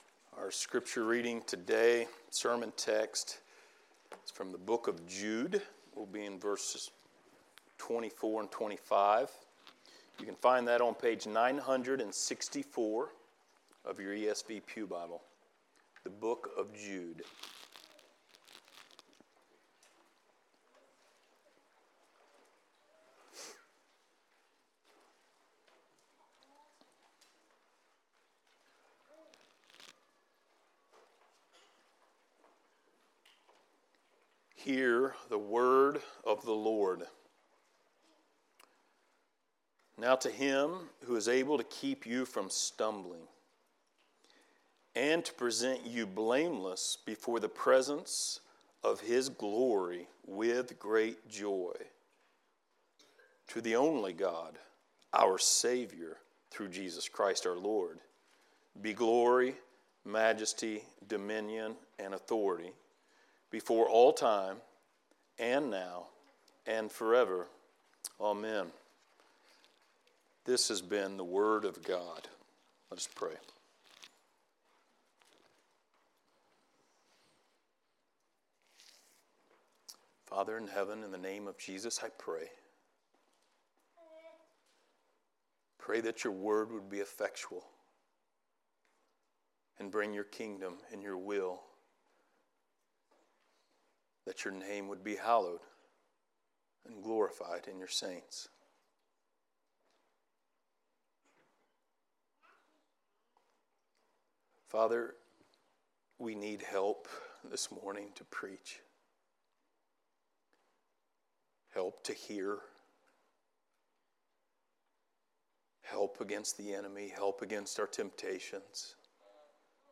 Jude 24-25 Service Type: Sunday Morning Related Topics